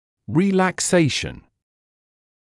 [ˌriːlæk’seɪʃn][ˌриːлэк’сэйшн]расслабление; релаксация